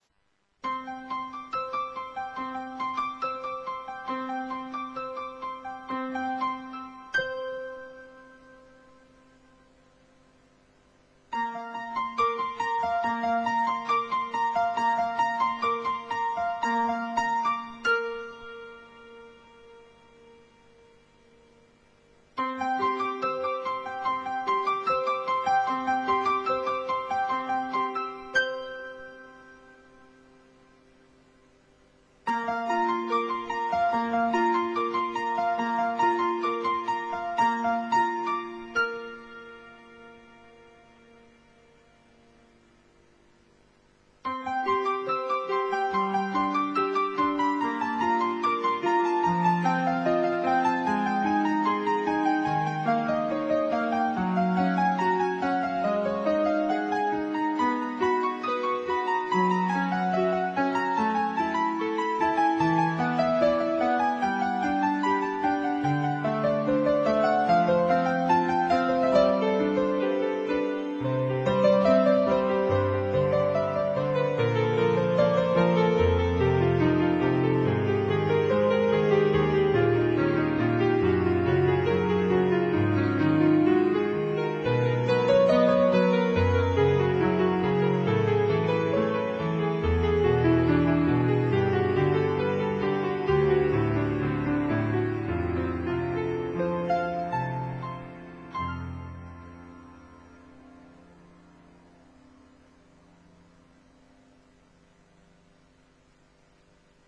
Languages: Instrumental